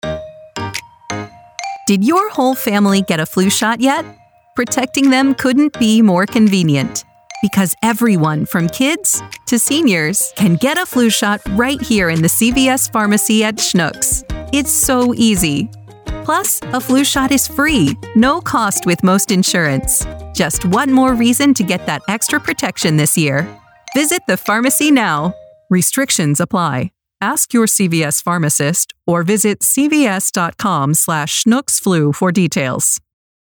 Female Voice Over, Dan Wachs Talent Agency.
Sassy, Dramatic, Conversational.
In-Store